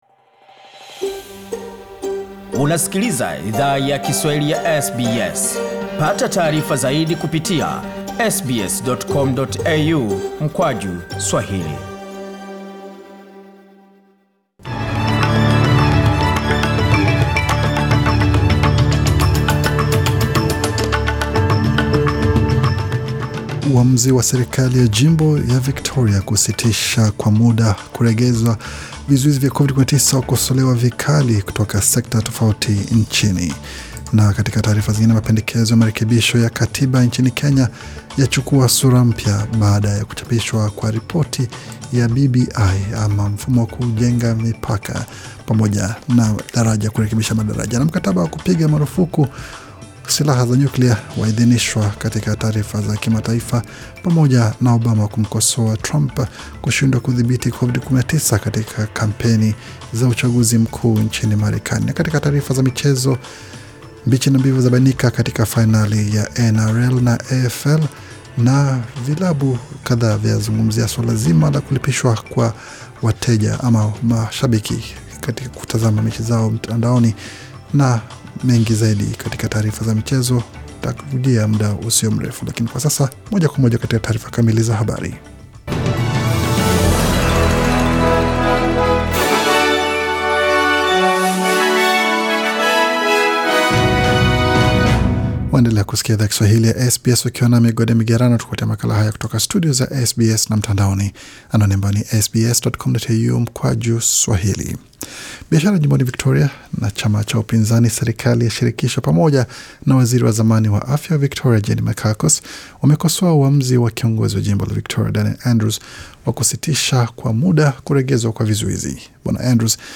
Taarifa ya habari 25 Oktoba 2020